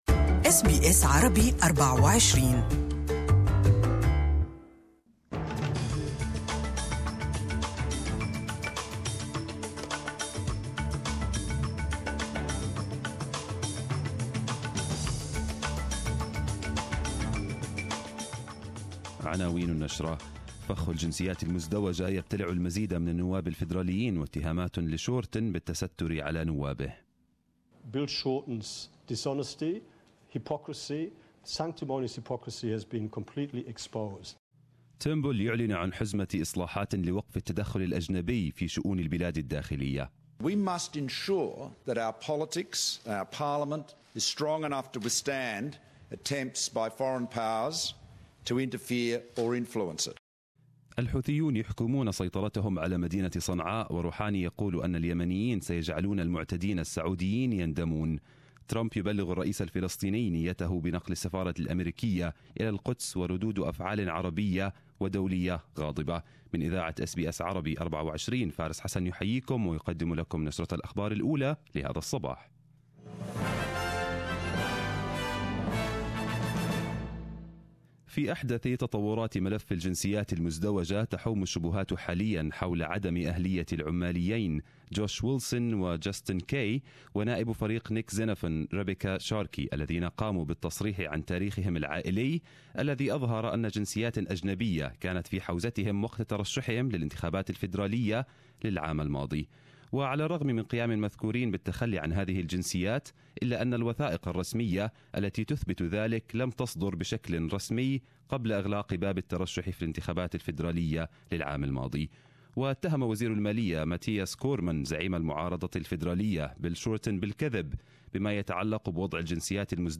Arabic News Bulletin 06/12/2017